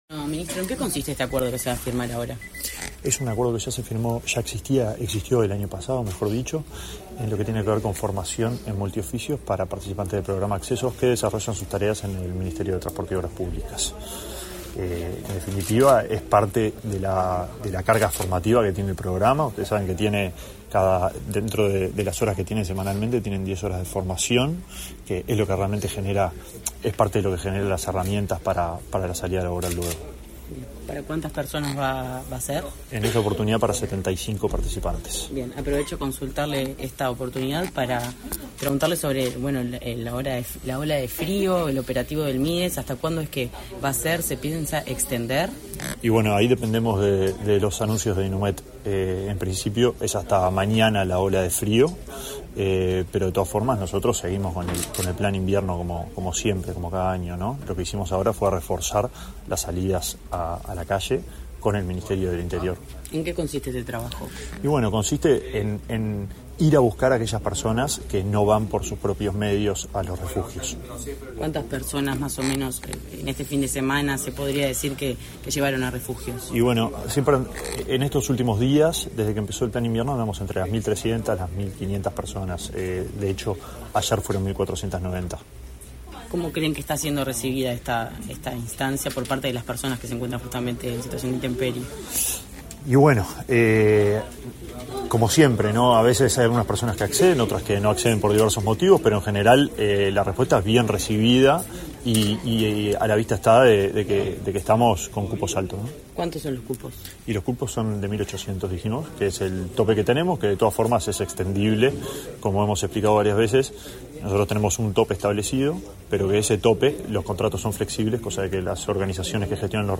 Declaraciones a la prensa del ministro de Desarrollo Social, Alejandro Sciarra
Declaraciones a la prensa del ministro de Desarrollo Social, Alejandro Sciarra 27/05/2024 Compartir Facebook X Copiar enlace WhatsApp LinkedIn El Ministerio de Desarrollo Social y el de Transporte y Obras Públicas firmaron, este 27 de mayo, un convenio con la UTU. Antes del evento, el titular de la primera cartera mencionada, Alejandro Sciarra, realizó declaraciones a la prensa.